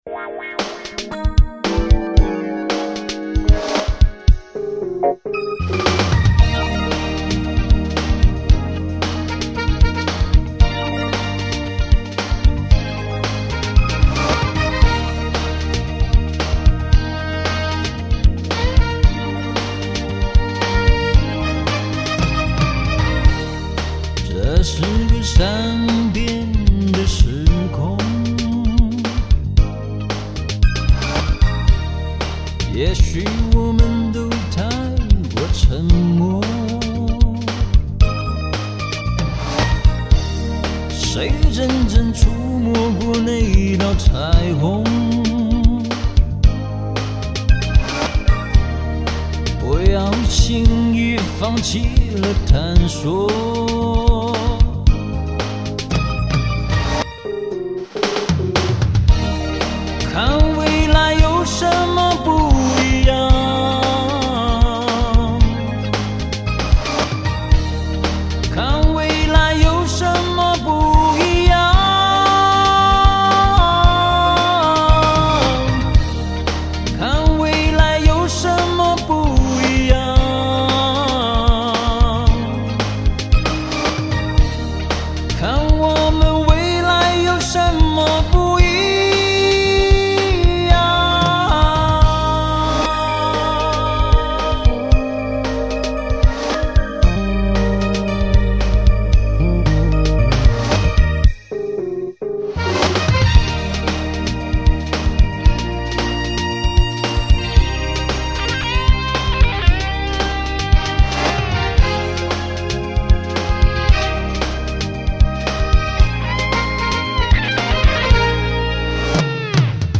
明亮透彻的旋律